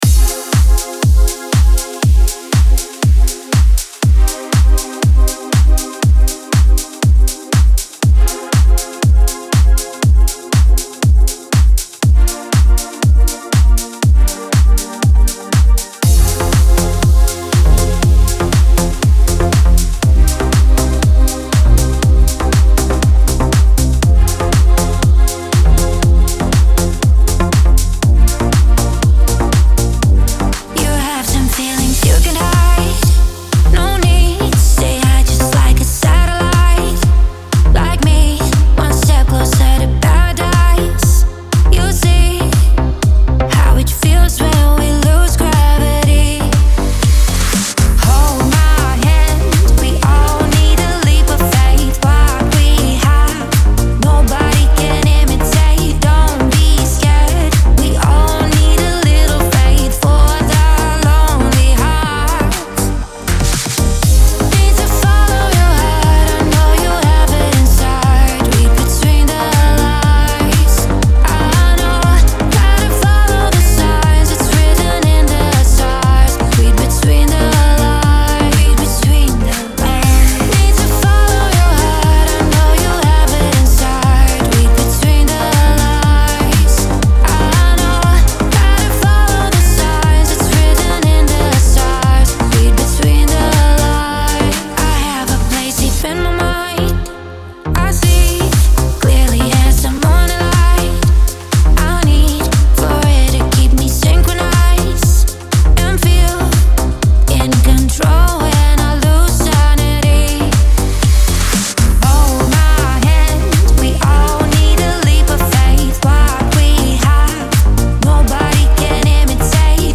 pop , dance